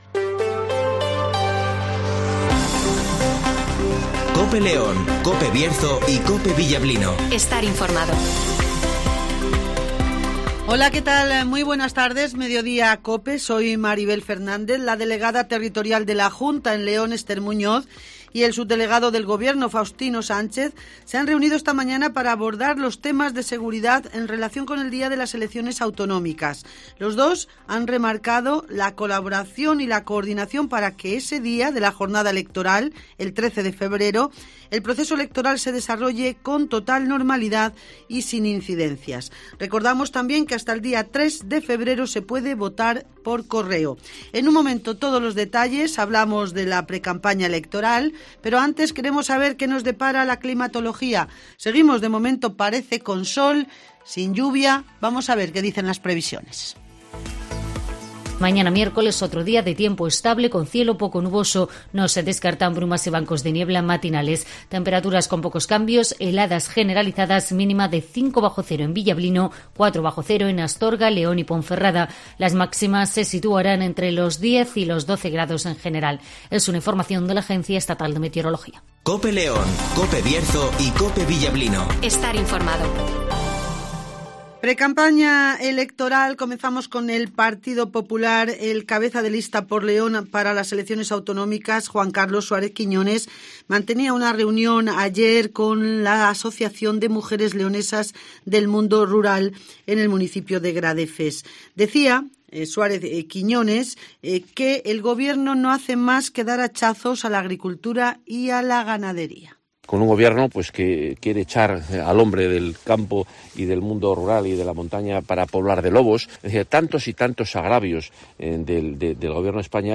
- Virginia Barcones ( Vicepresidenta General PSOE )